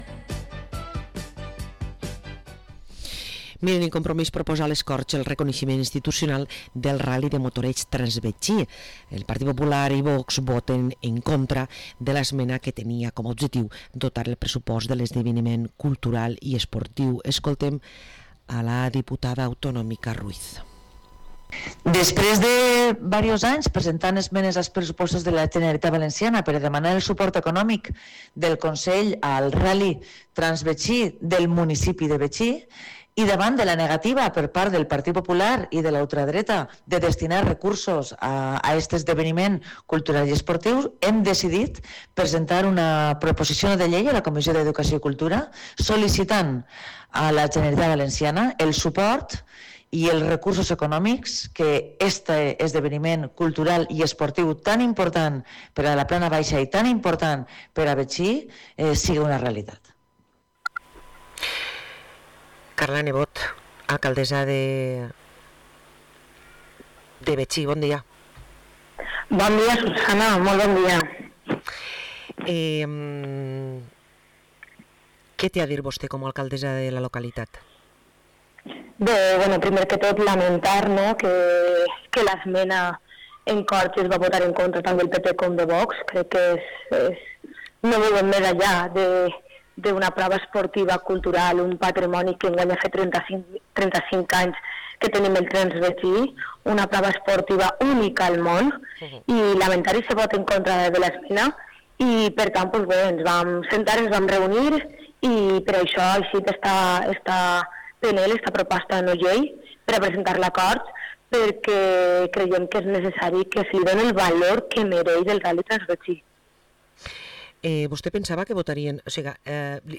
Parlem amb l´alcaldessa de Betxí, Carla Nebot